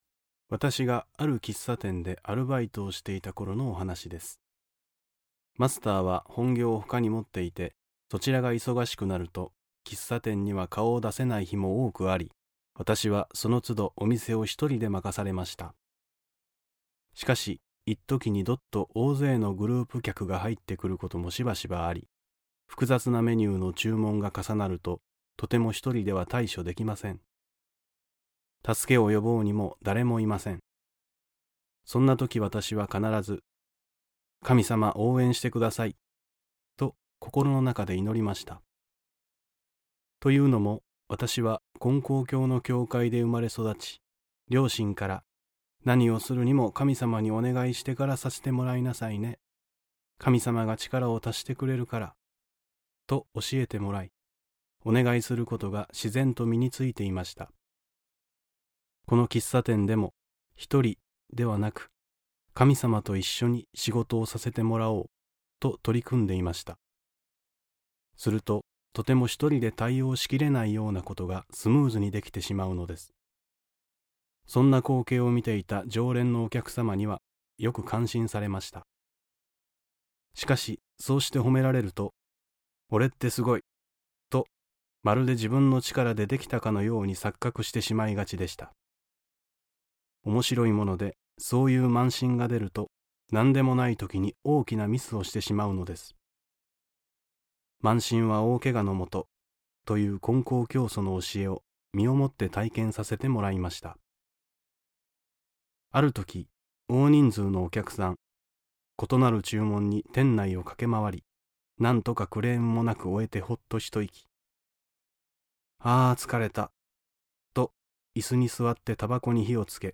●先生のおはなし